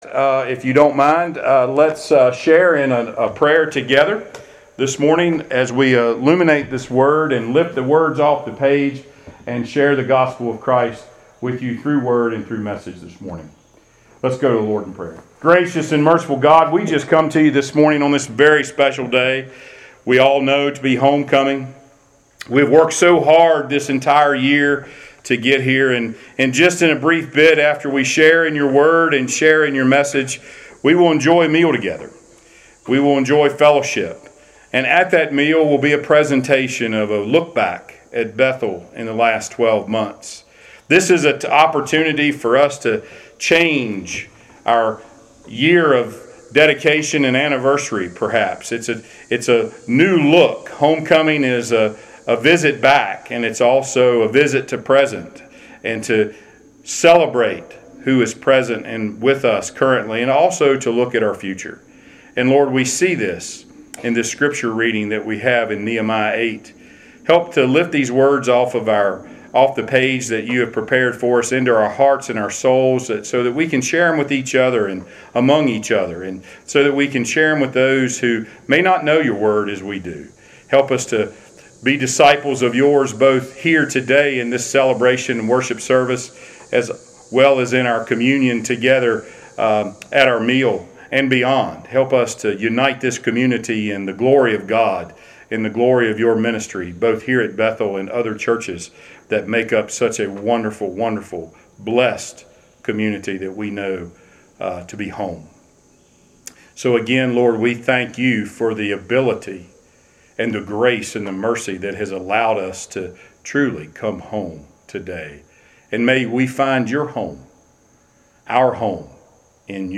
Passage: Nehemiah 8:1-12 Service Type: Sunday Worship